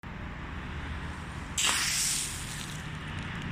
An einem gewissen Punkt dreht sich der Kessel von alleine, leert sich mit einem grossen „Splash“ in den Wasserweg unter ihm und spritzt den einen oder anderen Besucher nass.
Beim Zurückschwingen des Metallkessels in seine Ursprungsposition schlägt er an die Stahlkonstruktion und löst, wie auch das Shishi Odoshi, einen Klang an die Vergangenheit aus.
Wasser.mp3